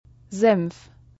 [ɱ] stimmhafter labio-dentaler Nasal